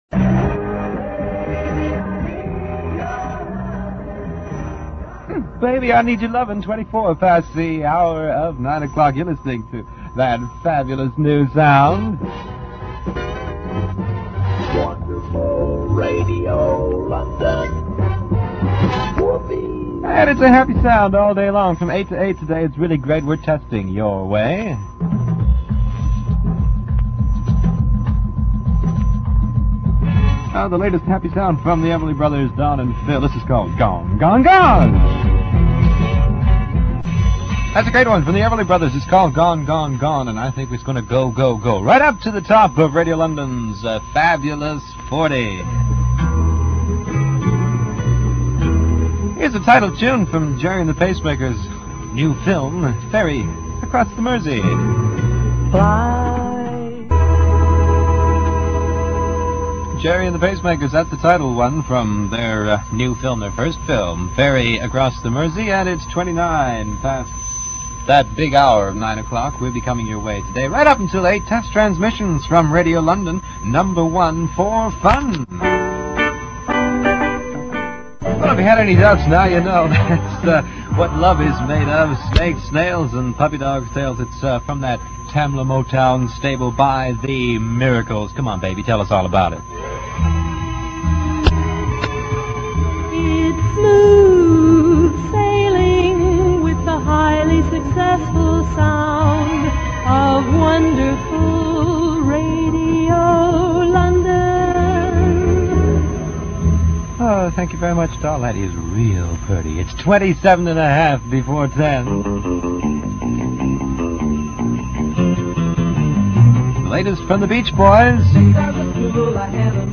click to hear audio Checking up on the opposition: a very early test transmission from Radio London in December 1964. The label on the tape box suggests that it is from the station's first day on air although this can not be confirmed.